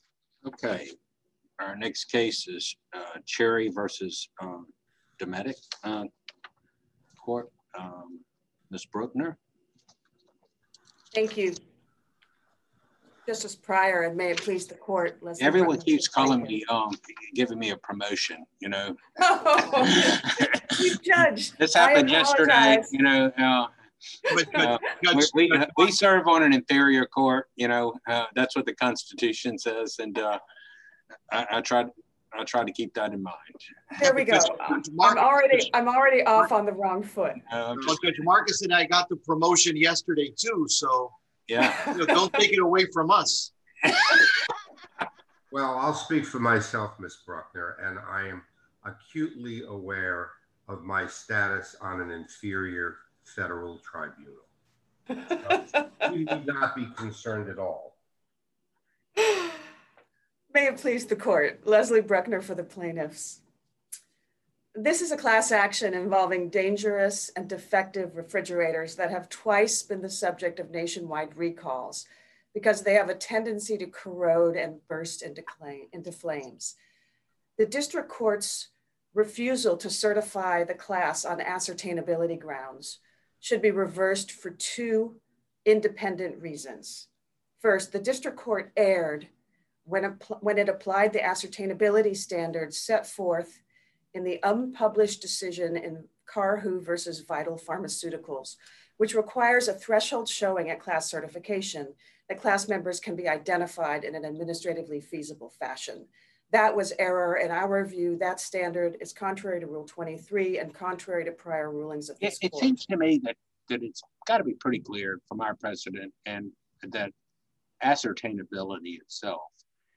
Oral Argument Recordings | Eleventh Circuit | United States Court of Appeals